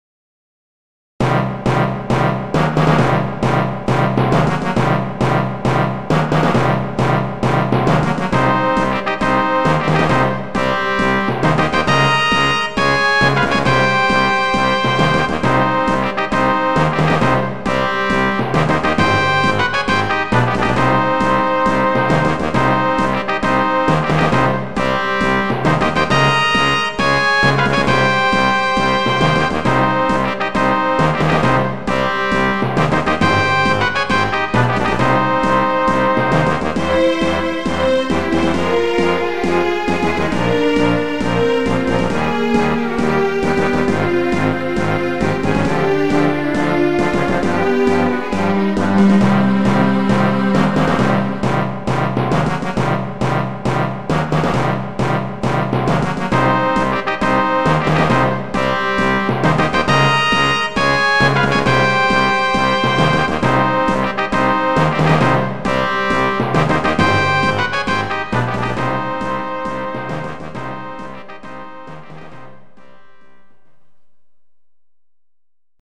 重厚な曲だけど、重苦しくないのがすばらしい。GS音源。